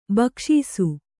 ♪ bakṣīsu